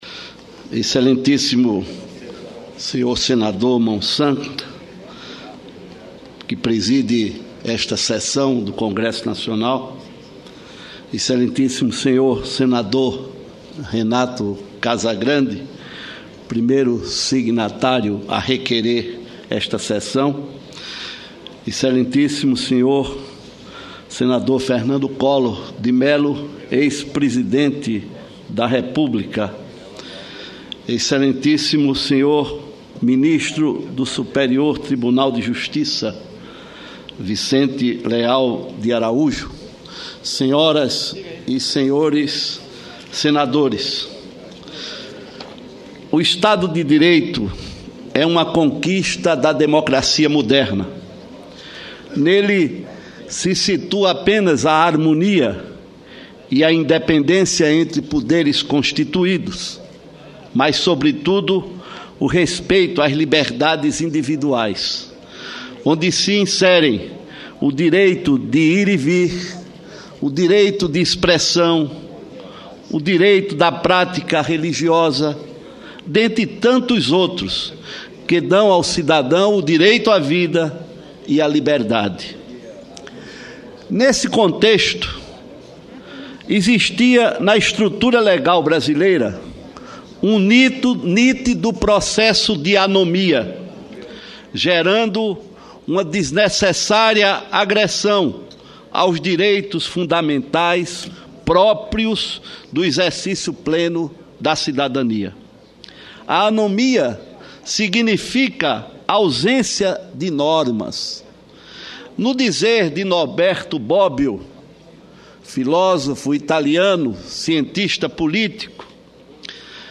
Pronunciamento do senador João Faustino